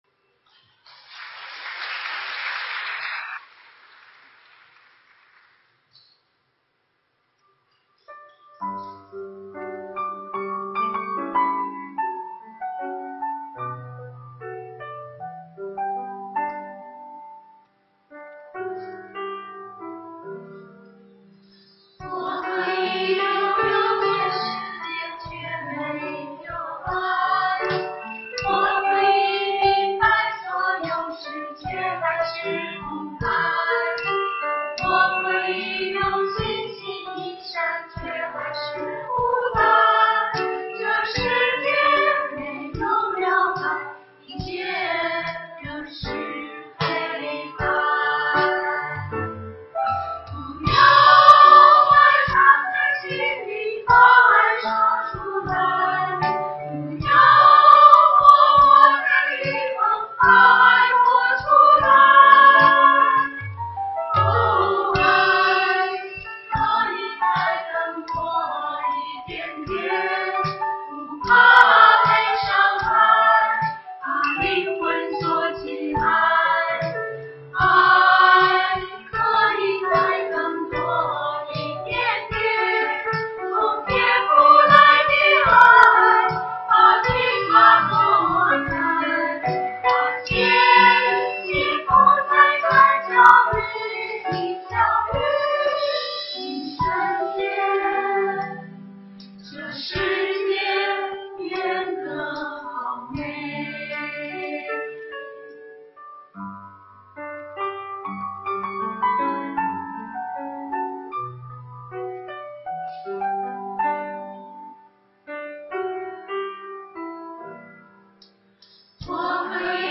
中秋赞美会
团契名称: 联合诗班
诗班献诗